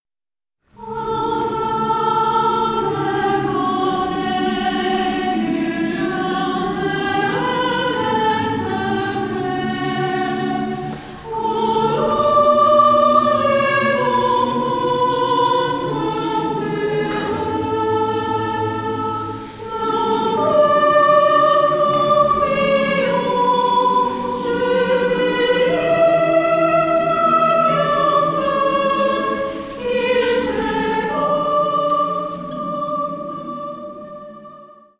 • kurzweilige Zusammenstellung verschiedener Live-Aufnahmen
Chor